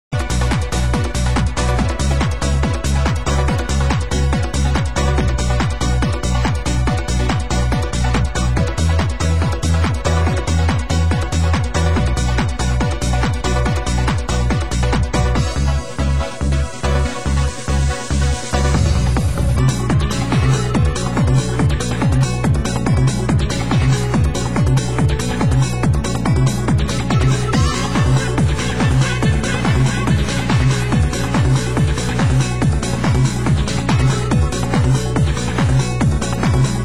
Genre: Progressive